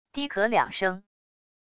低咳两声wav音频